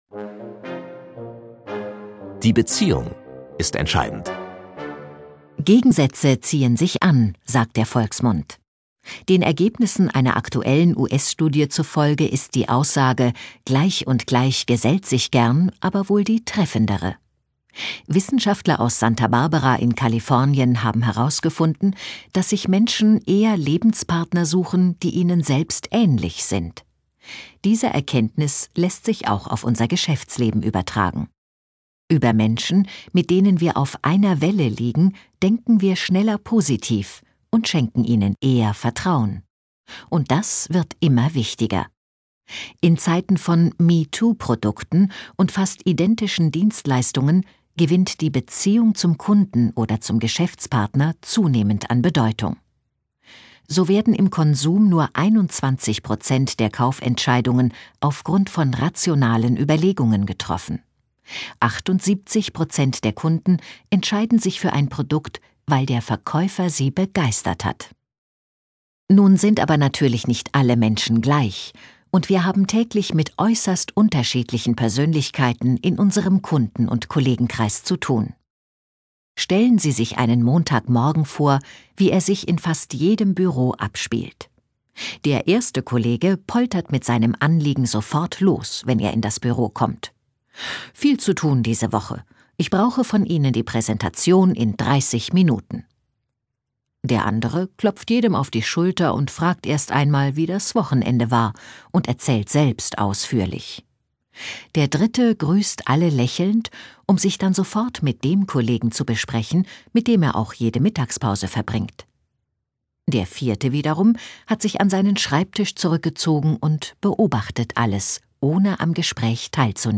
Die Erfolgsmacher II - Von den Besten profitieren Hörprobe (7:27 min.)
Vortrag